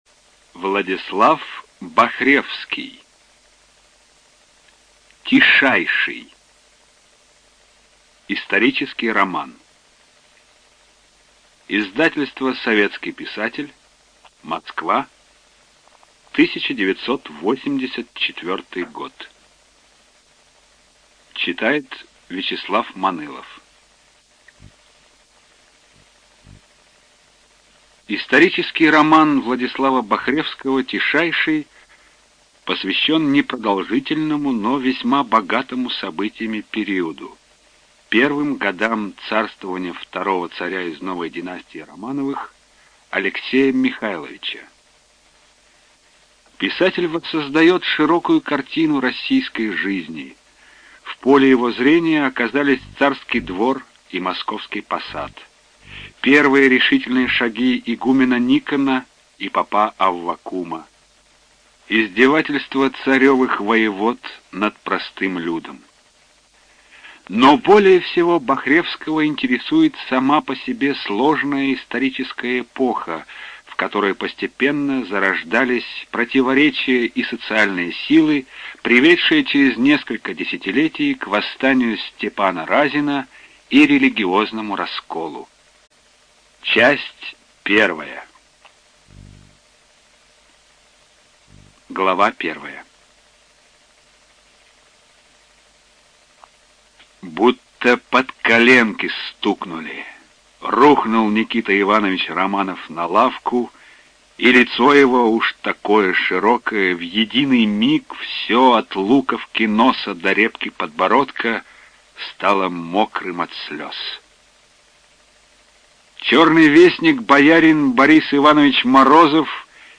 ЖанрИсторическая проза
Студия звукозаписиРеспубликанский дом звукозаписи и печати УТОС